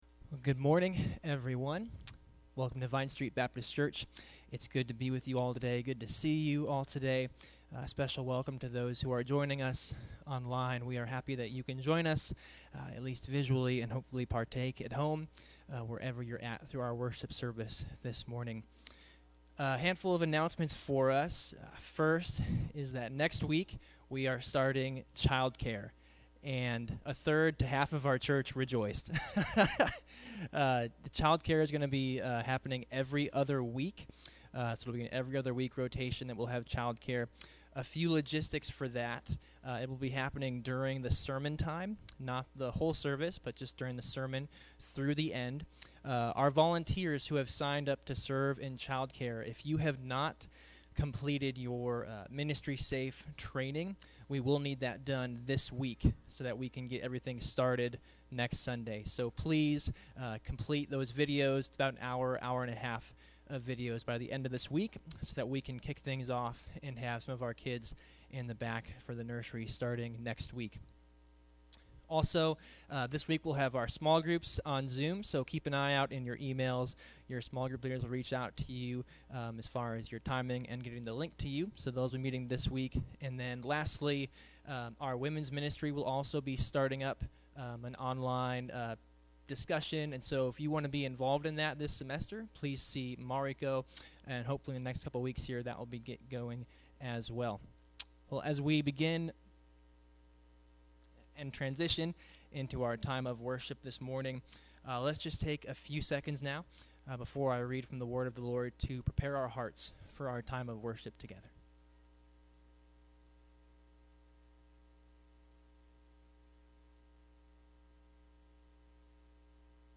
January 24 Worship Audio – Full Service